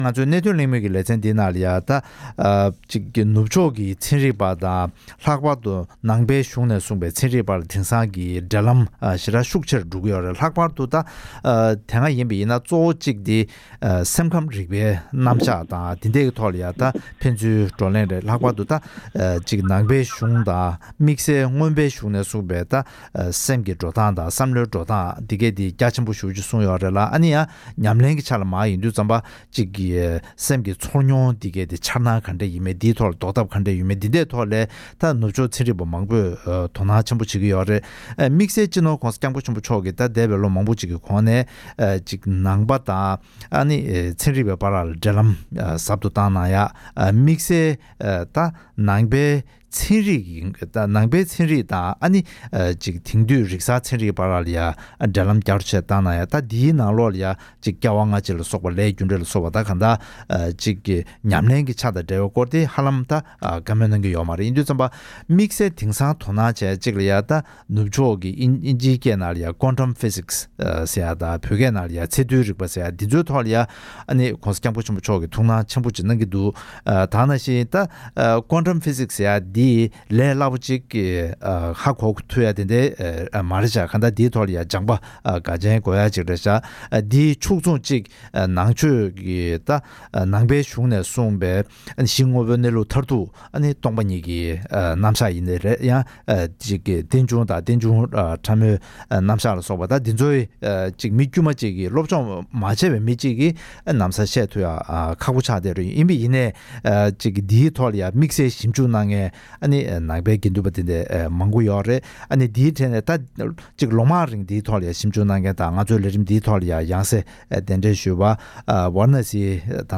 ༄༅། །དེ་རིང་གནད་དོན་གླེང་མོལ་གྱི་ལེ་ཚན་ནང་།